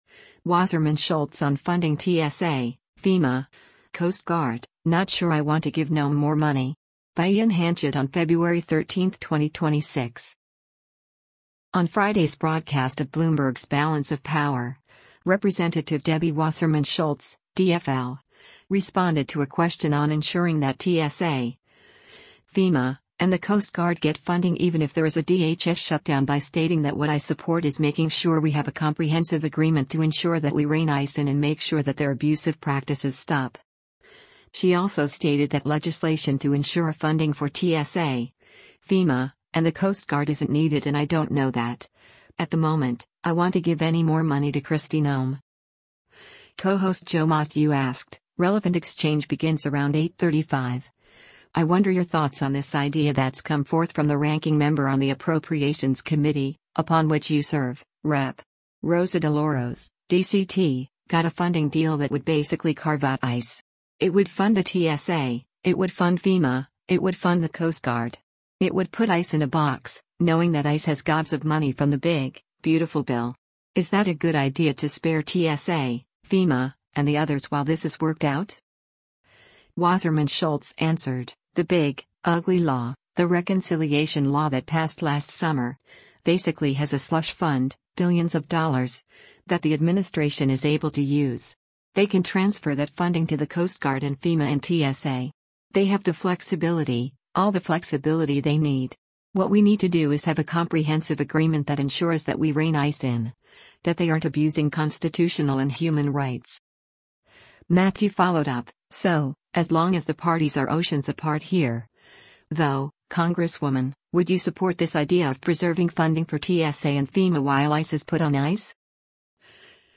On Friday’s broadcast of Bloomberg’s “Balance of Power,” Rep. Debbie Wasserman Schultz (D-FL) responded to a question on ensuring that TSA, FEMA, and the Coast Guard get funding even if there is a DHS shutdown by stating that “What I support is making sure we have a comprehensive agreement to ensure that we rein ICE in and make sure that their abusive practices stop.”